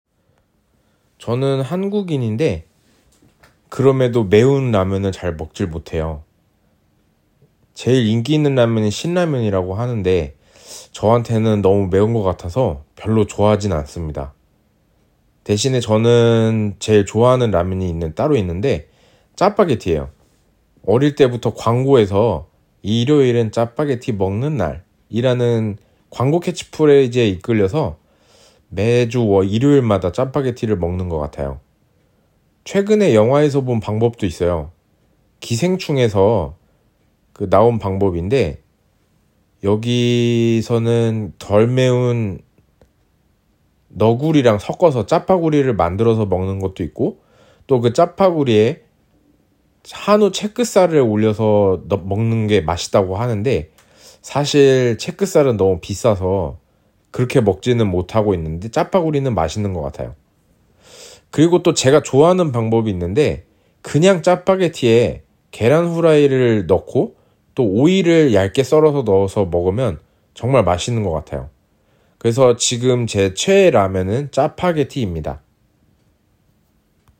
hana47_interview_01.mp3